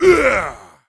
attack_2.wav